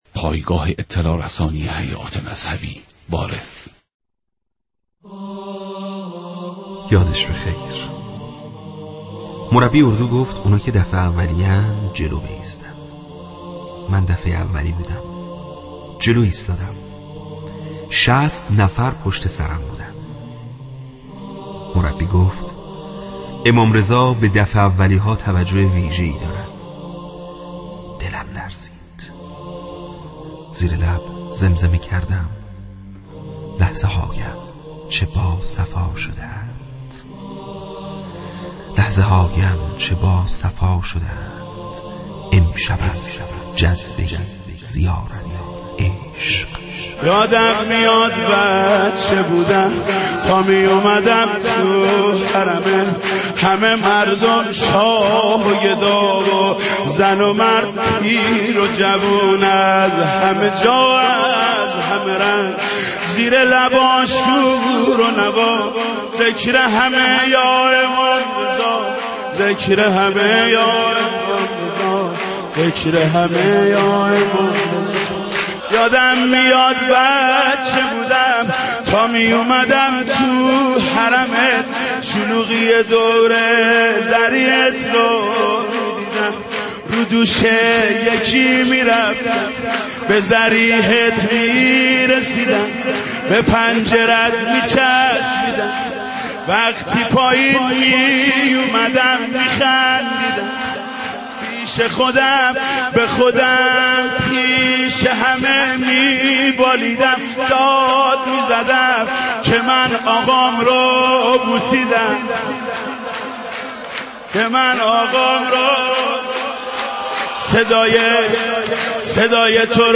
دانلود صوت امام رضا زیارت زیارت امام رضا مداحی حاج محمود کریمی محمود کریمی اخبار مرتبط انقلاب درونی مناجات خوان معروف تهران نماز عید فطر چگونه است؟